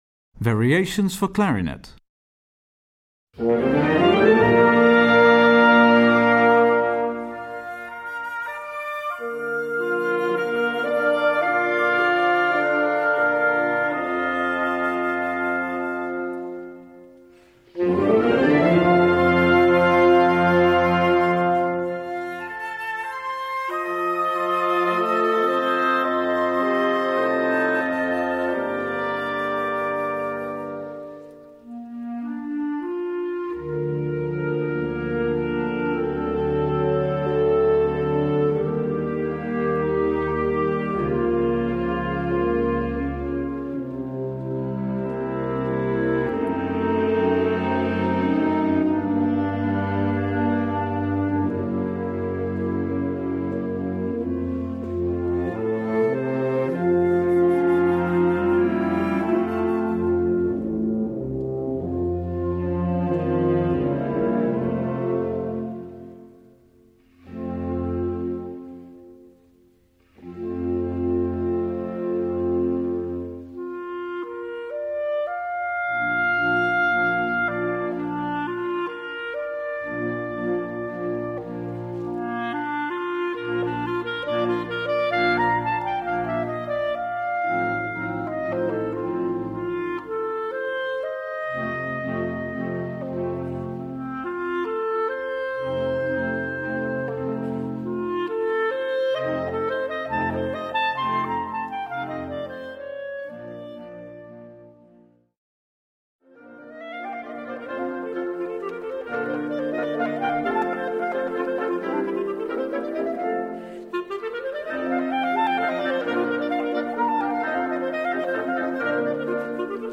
Key: B-flat Major (original key: C Major)
This arrangement is transposed to B-flat Major.